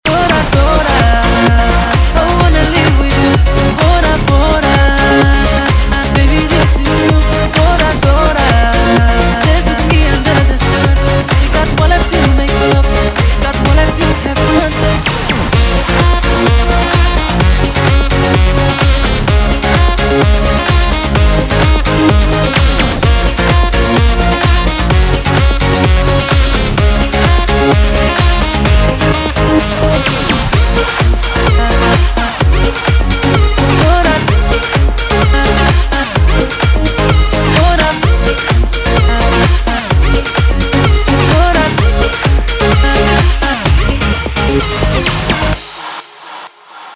• Качество: 192, Stereo
ElectroPop!